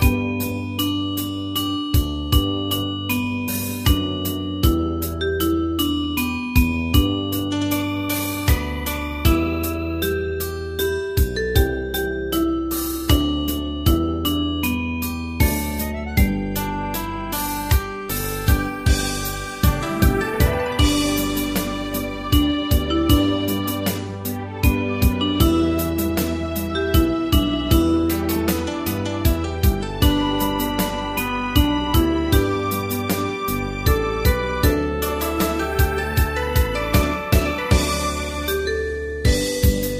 カテゴリー: ユニゾン（一斉奏） .
歌謡曲・演歌